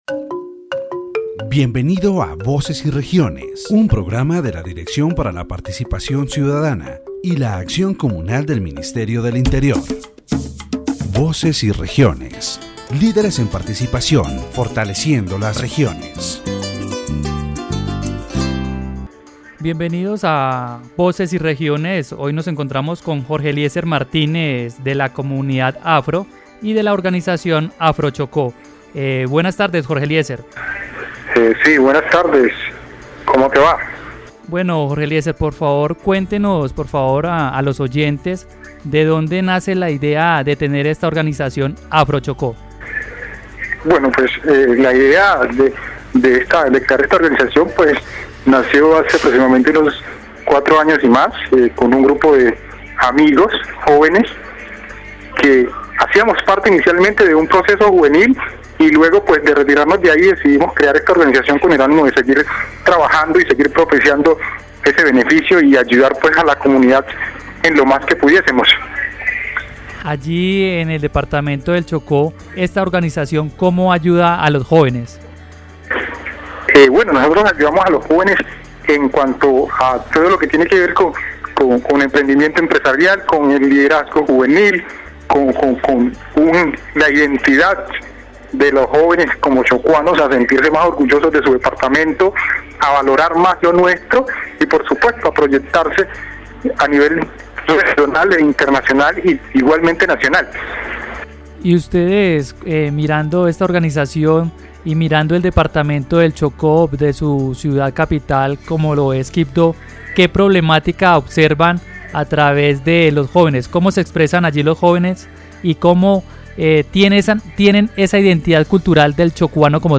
In the interview, the main challenges of the department are addressed, such as the lack of employment and education, and the low youth political participation.